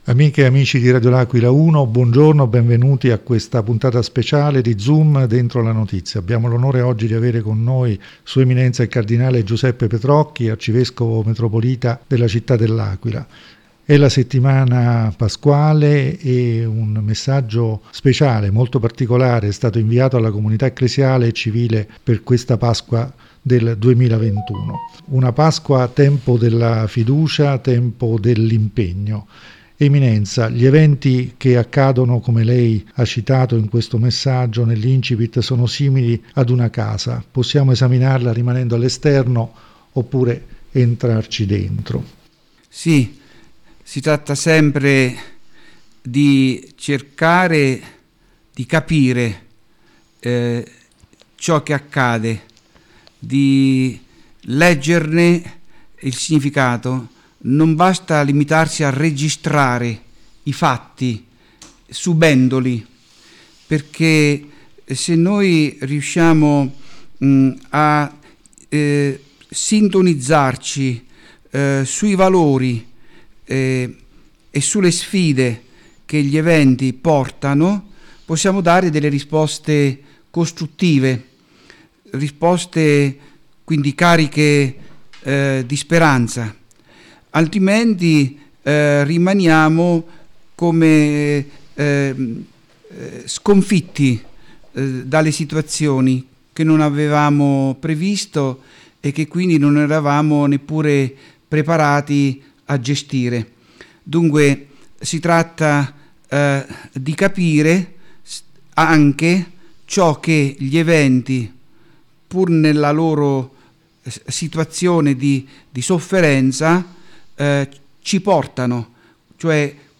Il messaggio di S.E. il Cardinale Giuseppe Petrocchi, Arcivescovo Metropolita di L’Aquila, alla Comunità Ecclesiale e Civile per la Pasqua 2021. L’intervista